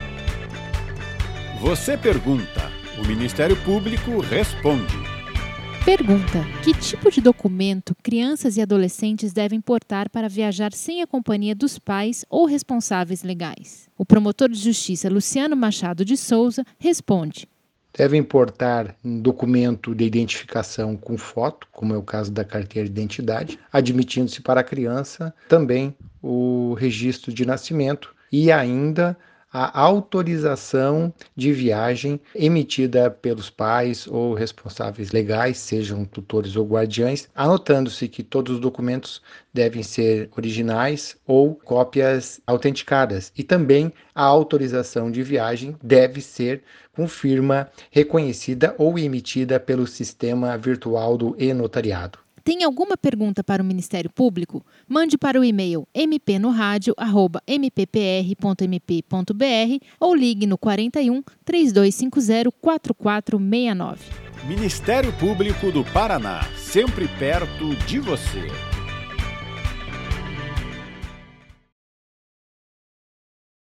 Em áudios curtos, de até um minuto, procuradores e promotores de Justiça esclarecem dúvidas da população sobre questões relacionadas às áreas de atuação do Ministério Público.
Ouça abaixo a resposta da promotor de Justiça, Luciano Machado de Souza: